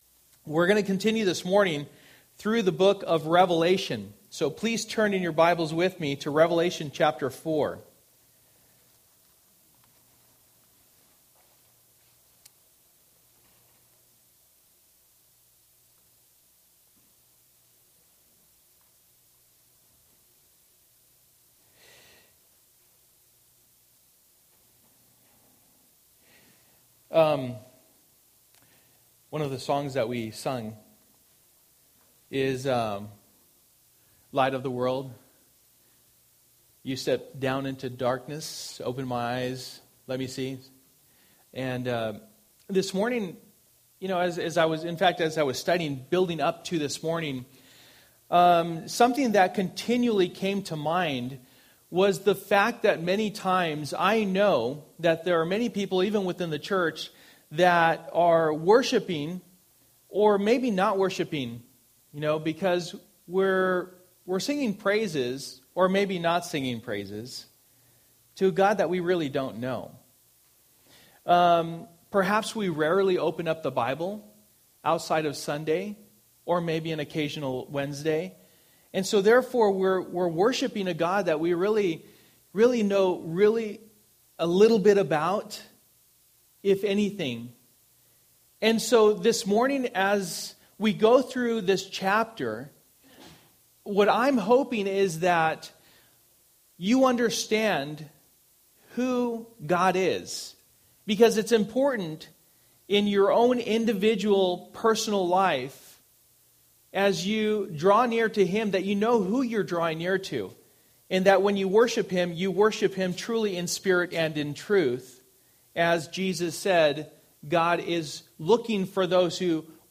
For the Time is Near Passage: Revelation 4:1-11 Service: Sunday Morning %todo_render% Download Files Bulletin « A Faithful Life Resonates to God’s Glory Worthy Is the Lamb!